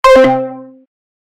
Goofy Ahh 2012 Android Low Battery Efeito Sonoro: Soundboard Botão
Goofy Ahh 2012 Android Low Battery Botão de Som